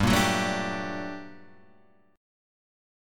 G Minor Major 9th